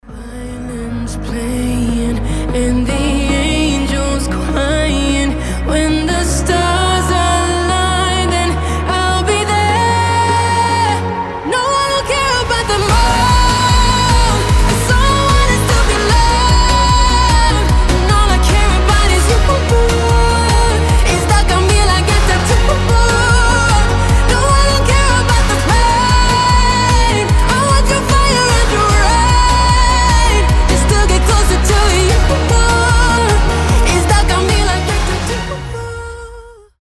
мощные
Dance Pop
красивый женский вокал
сильный голос
танцевальные